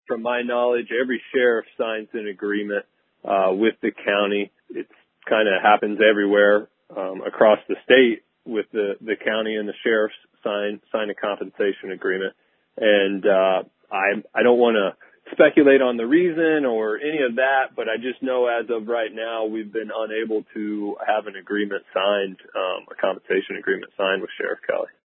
Three Comments From Commissioner Jordan Brewer